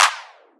DDW Clap 1.wav